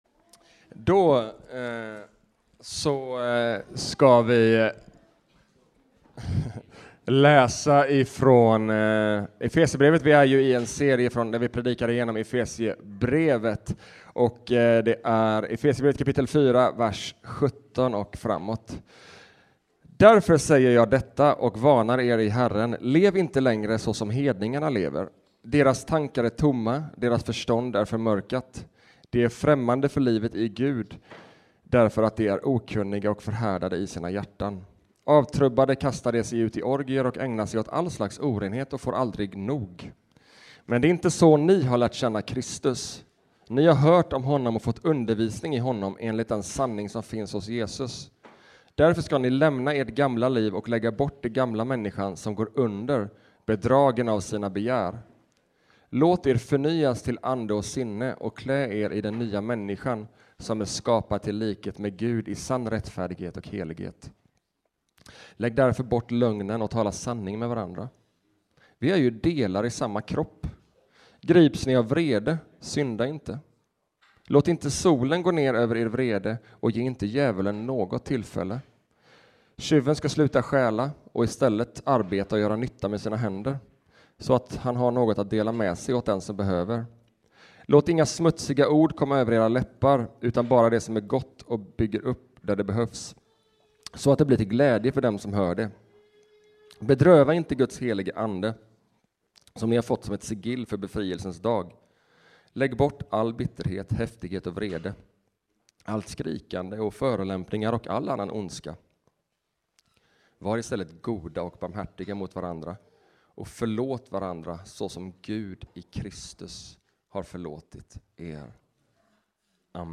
Efesierbrevet 4:17-32 Predikan